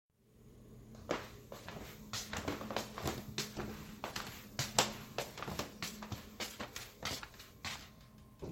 美发师FX " 塑料鞋底在硬地板上的脚步声
描述：走在坚硬木地板上的塑料底鞋。 记录在H4N Zoom上。
Tag: 足迹 步骤